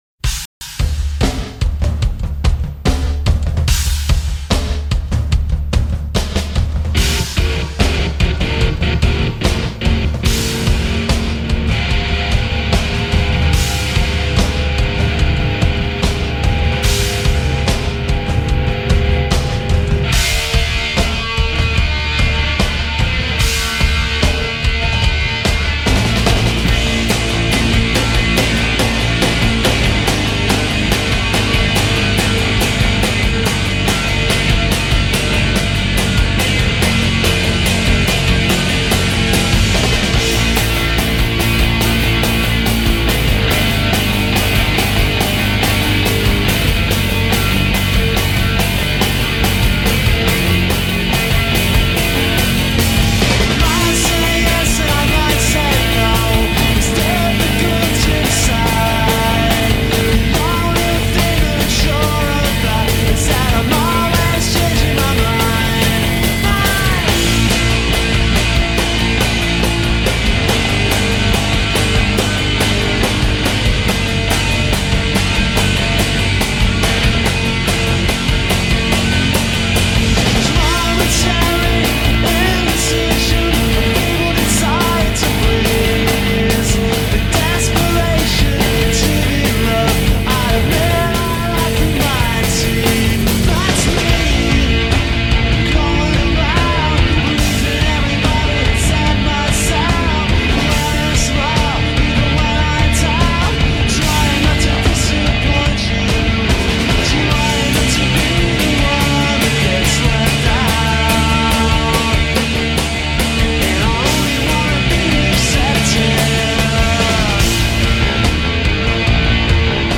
alternative rock band